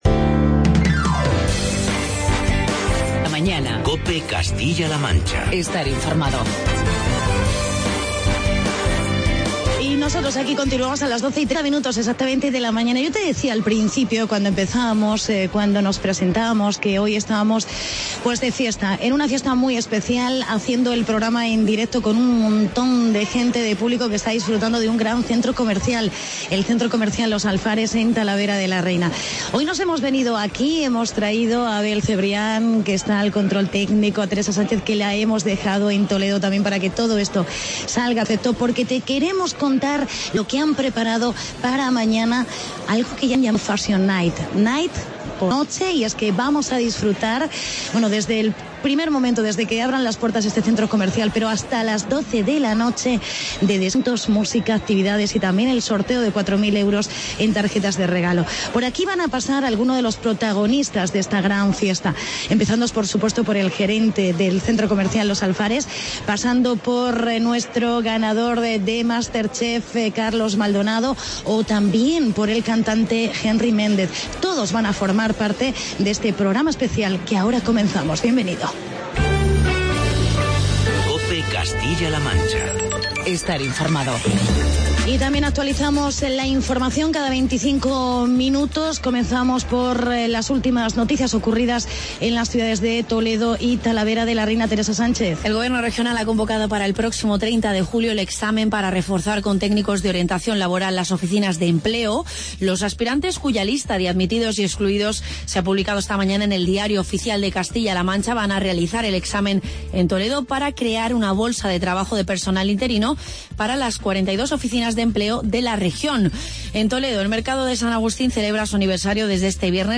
Progama especial desde el Centro Comercial Los Alfares de Talavera de la Reina.